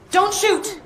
dont shoot arc raiders Meme Sound Effect
dont shoot arc raiders.mp3